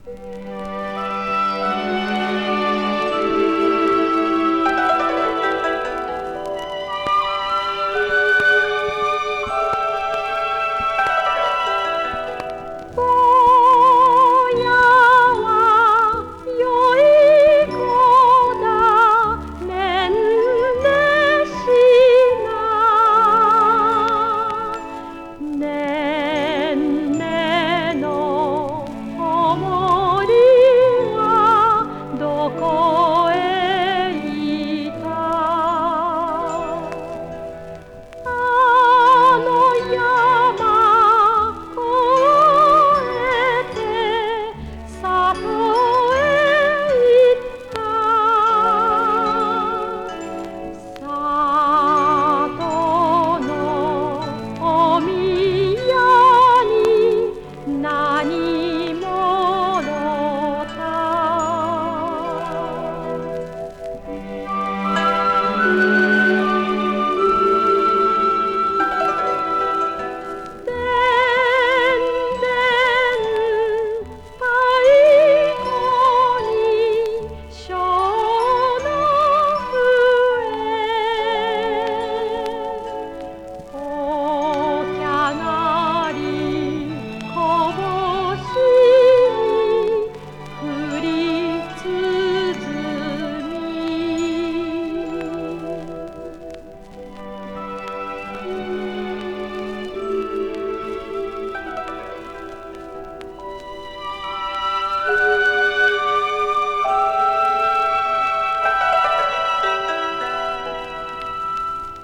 Жанр: Enka
в сопровождении традиционного японского инструмента кото.
Koto
Soprano Vocals